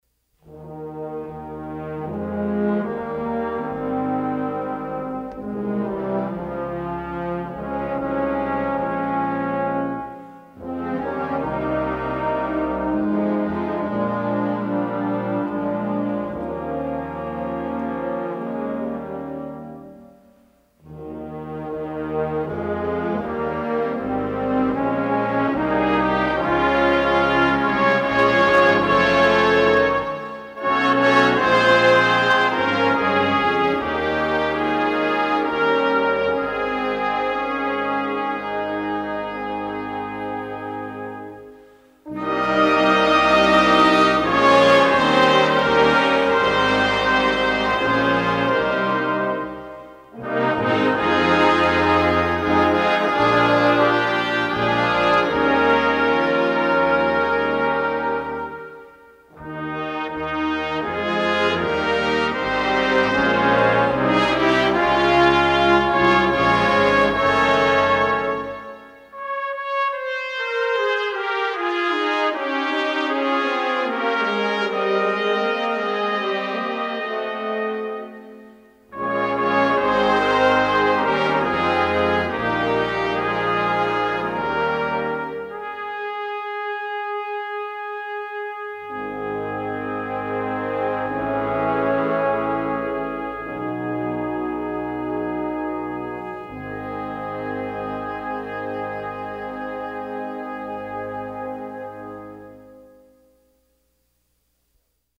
Besetzung: Blasorchester
A contemporary arrangement of the classic American folk tune